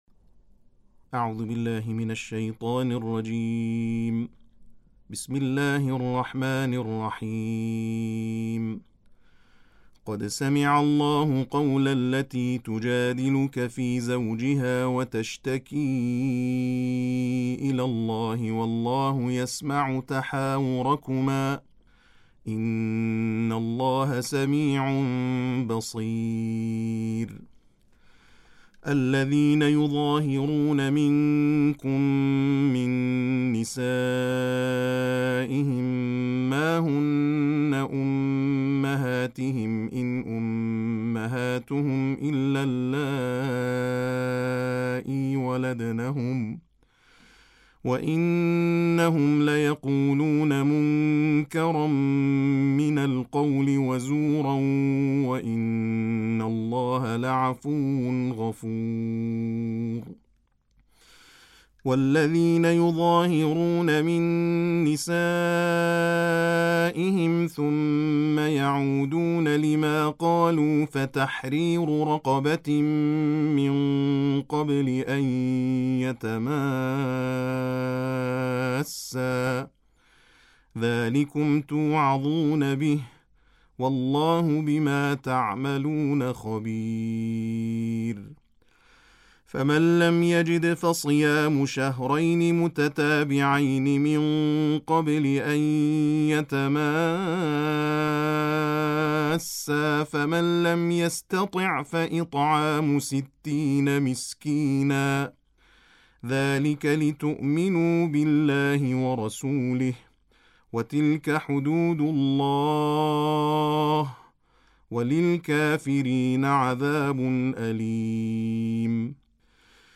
تلاوت ترتیل جزء بیست‌وهشتم قرآن
صوت تلاوت ترتیل جزء بیست و هشتم